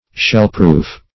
Shellproof \Shell"proof`\, a.